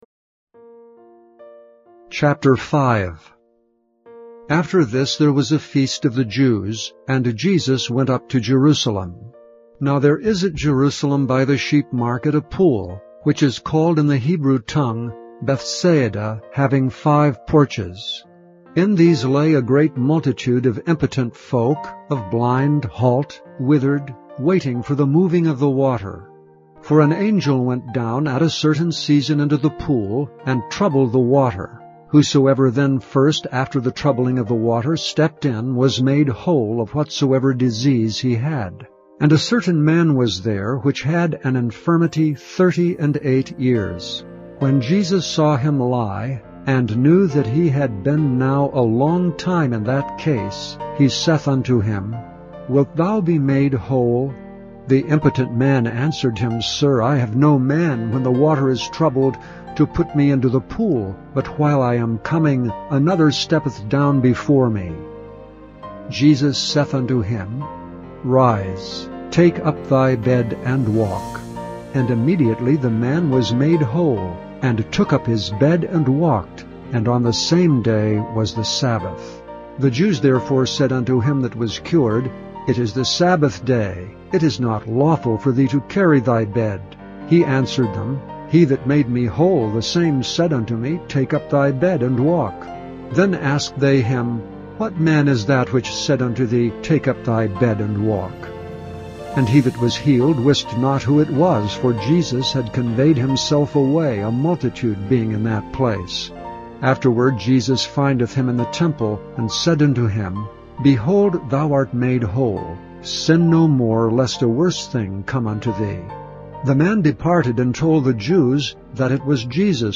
Listen to John 5 Listen to John chapter five being read, or download it to listen to later.